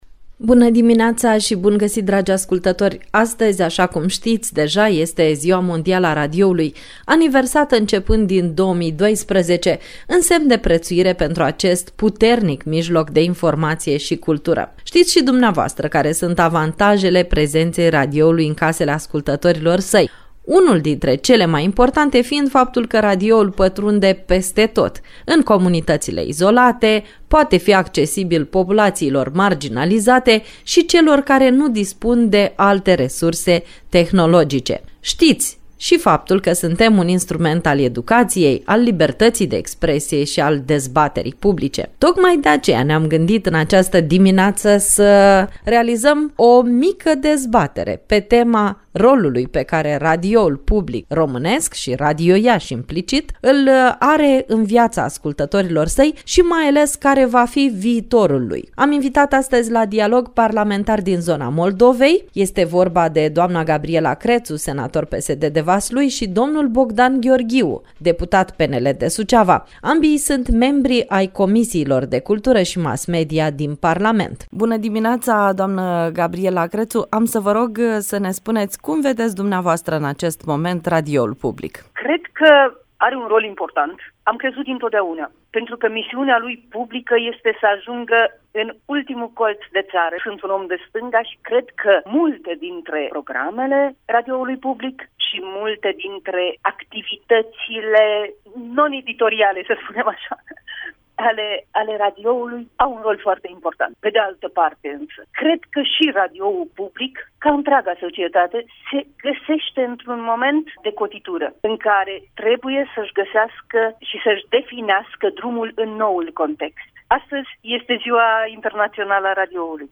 La Tema zilei, în emisiunea Bună Dimineața, am vorbit despre ce reprezintă azi radioul public și care este viitorul lui, cu doi parlamentari din zona Moldovei, membri ai Comisiilor de cultură și mass media, din cele doua camere.